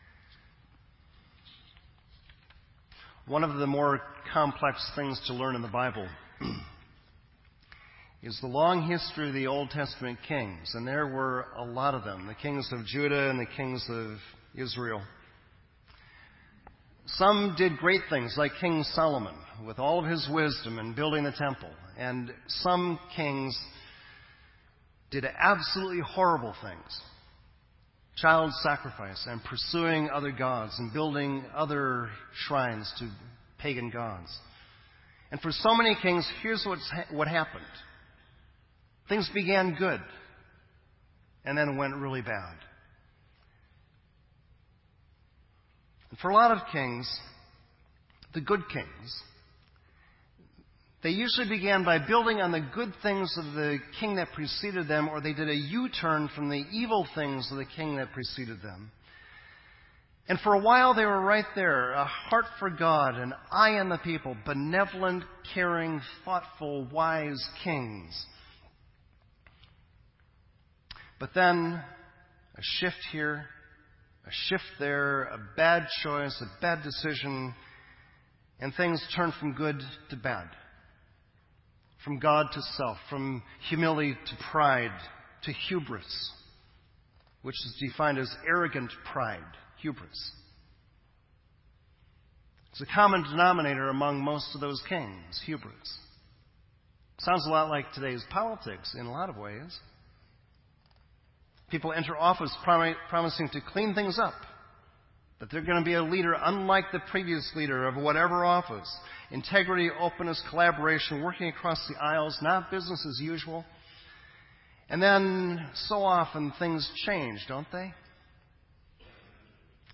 This entry was posted in Sermon Audio on October 2